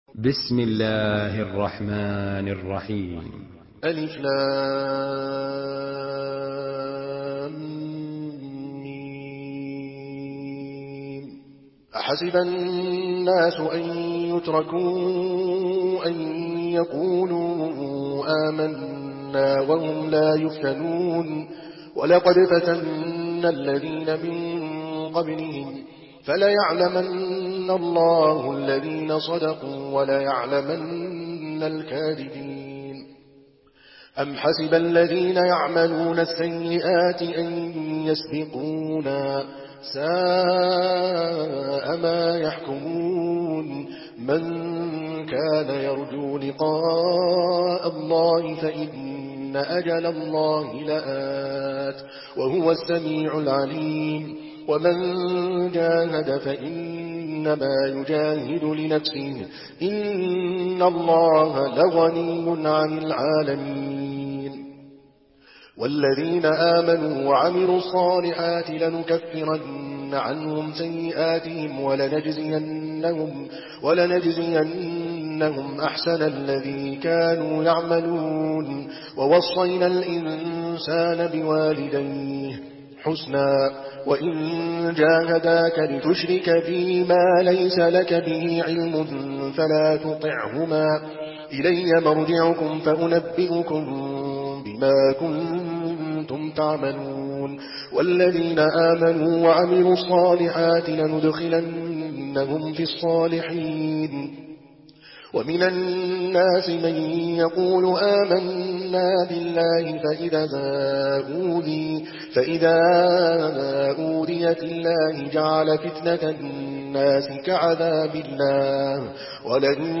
Surah আল-‘আনকাবূত MP3 in the Voice of Adel Al Kalbani in Hafs Narration
Surah আল-‘আনকাবূত MP3 by Adel Al Kalbani in Hafs An Asim narration.
Murattal